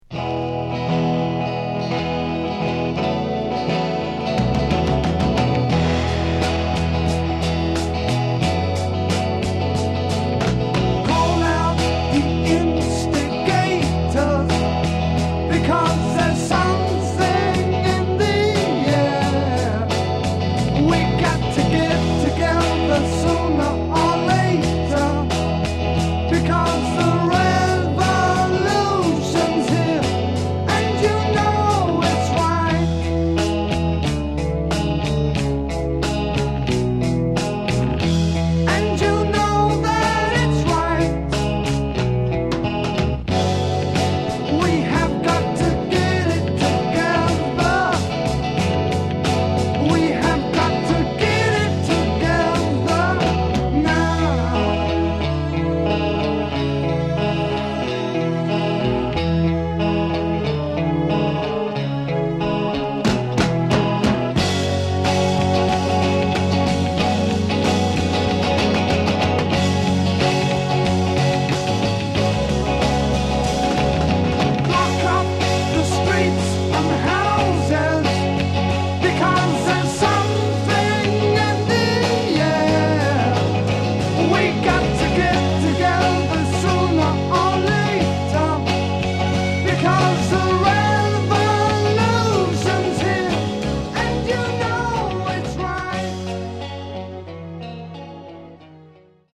The vinyl looks untouched, and the audio is pristine Mint.